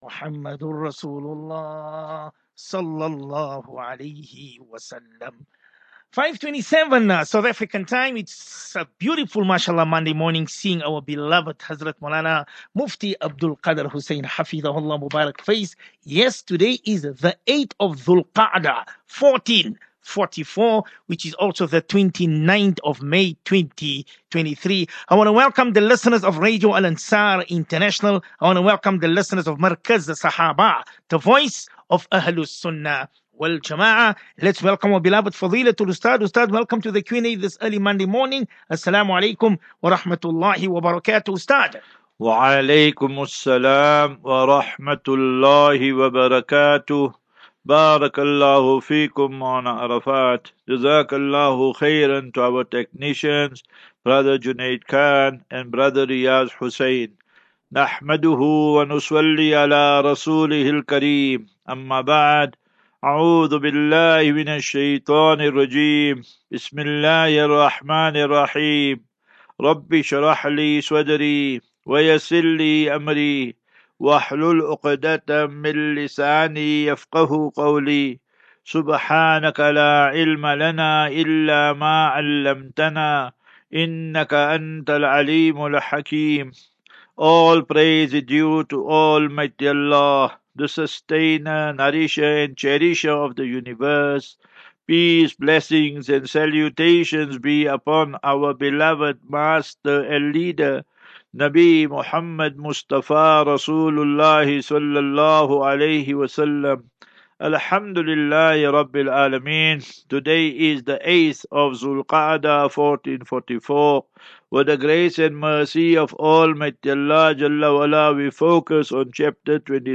View Promo Continue Install As Safinatu Ilal Jannah Naseeha and Q and A 29 May 29 May 23 Assafinatu